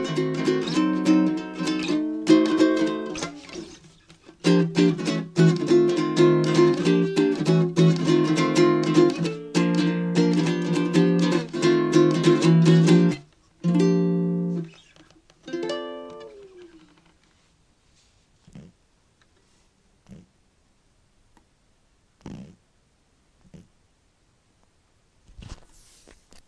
Baritone uke